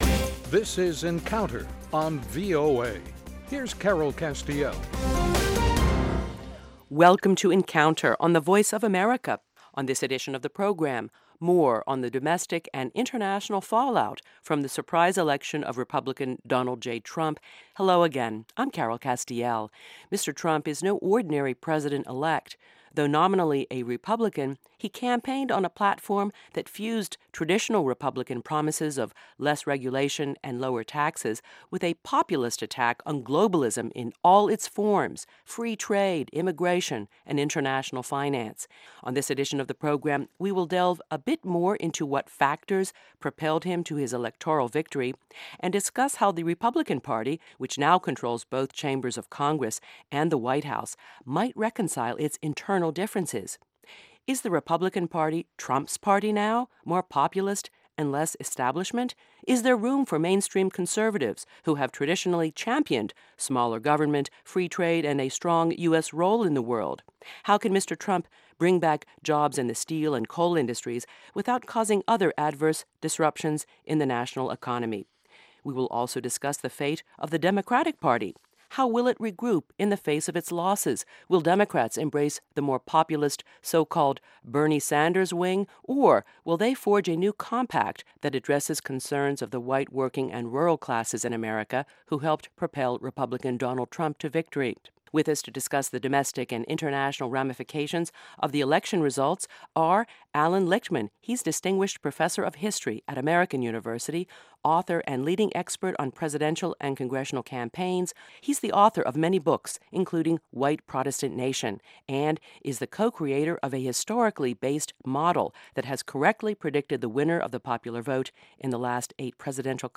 Issues that affect our lives and global stability are debated in a free-wheeling, unscripted discussion of fact and opinion.